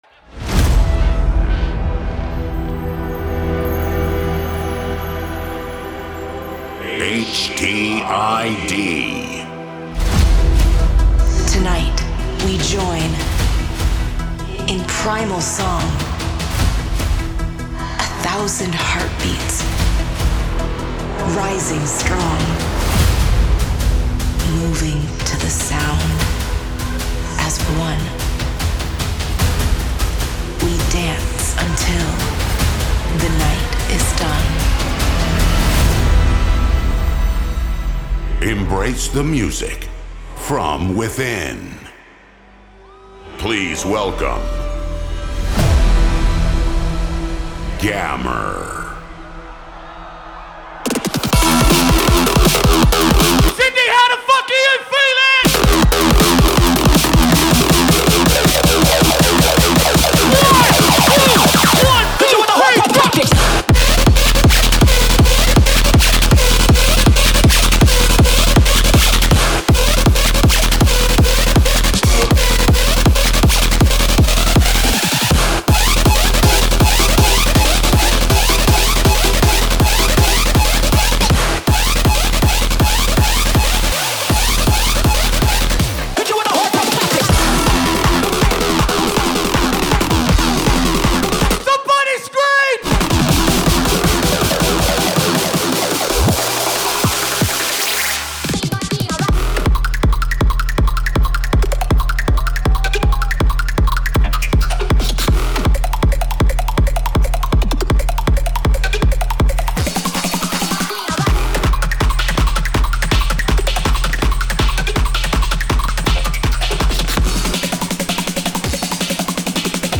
Also find other EDM